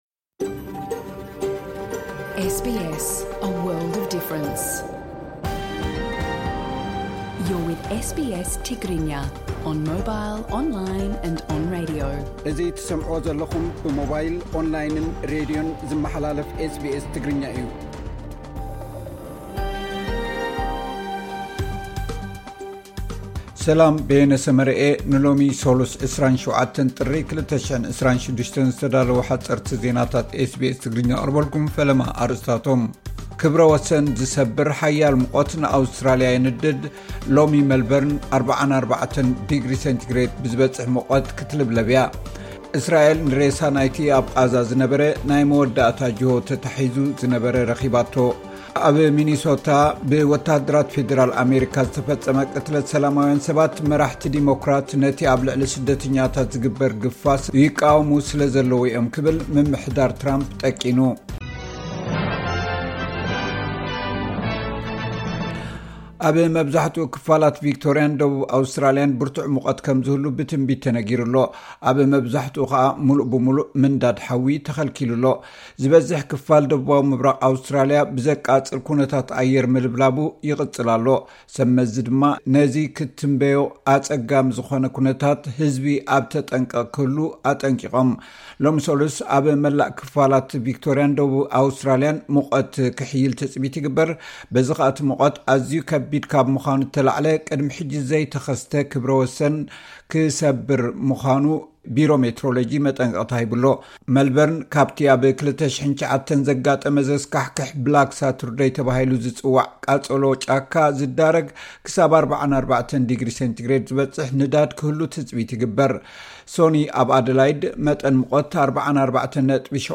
ክብረ ወሰን ዝሰብር ሓያል ሙቐት ንኣውስትራሊያ የንድዳ፡ ሎሚ መልበርን 44 ዲግሪ ሰንቲግሬድ ብዝበጽሕ ሙቐት ክትልብለብ'ያ። (ሓጸርቲ ዜናታት ኤ ቢ ኤስ ትግርኛ 27 ጥሪ 2026)